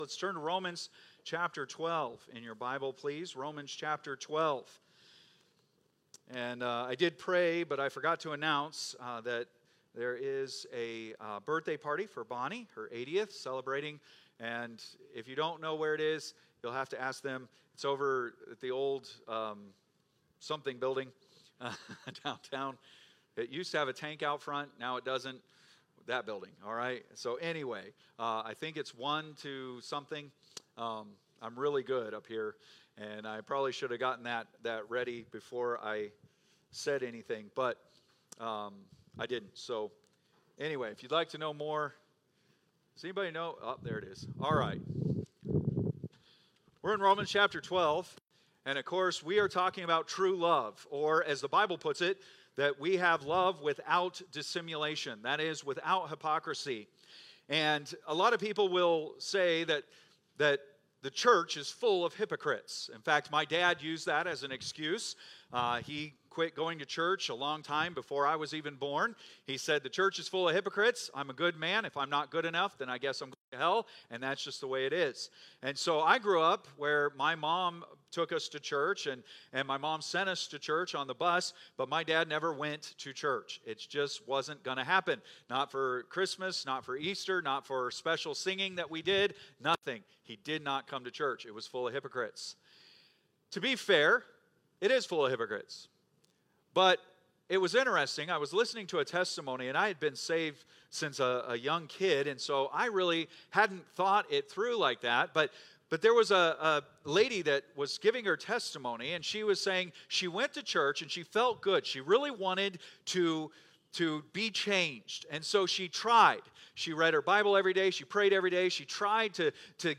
Date: August 3, 2025 (Sunday Morning)